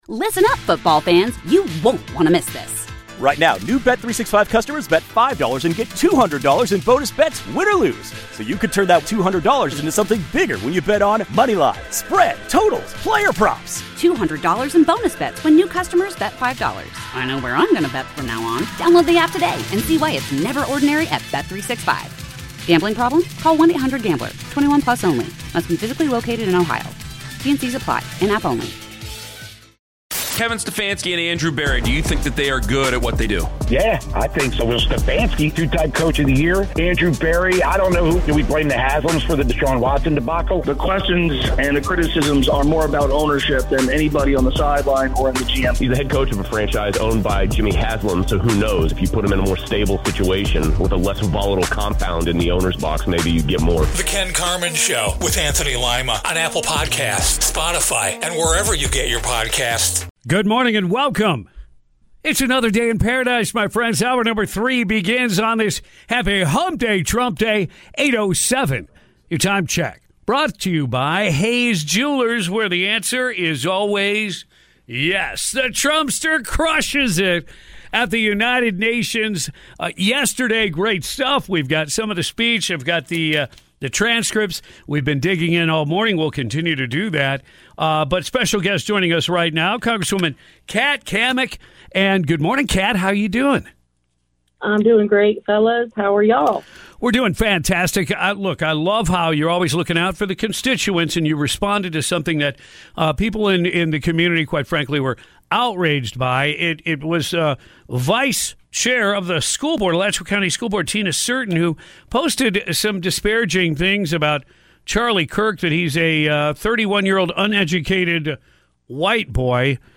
Weekday mornings from 6:00 to 10:00am, featuring breaking news, traffic and weather. And commentary that drives conversation on the biggest issues.